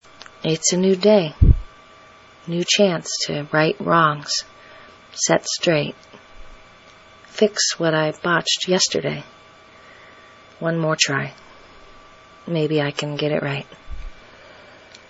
The following micropoetry is based on the Wed 7/30/14 fieryverse poetry prompt challenge as part of Spoken Word Wednesday.